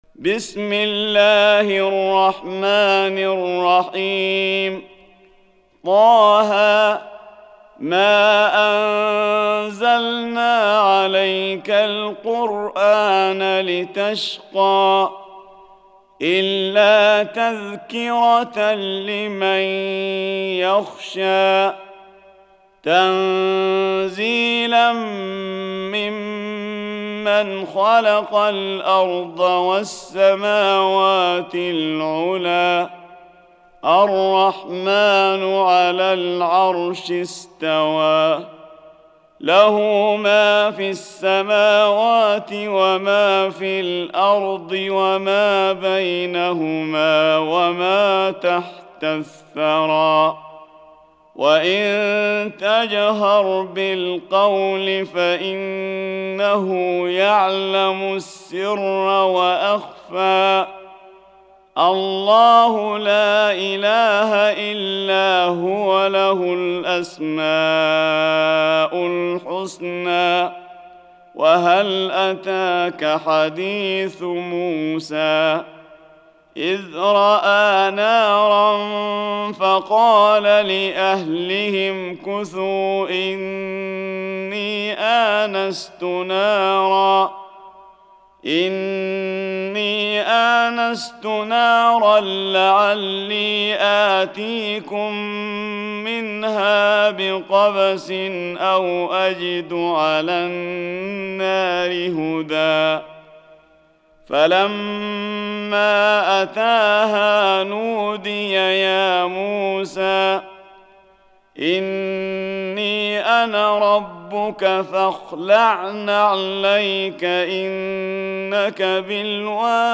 Surah Sequence تتابع السورة Download Surah حمّل السورة Reciting Murattalah Audio for 20. Surah T�H�. سورة طه N.B *Surah Includes Al-Basmalah Reciters Sequents تتابع التلاوات Reciters Repeats تكرار التلاوات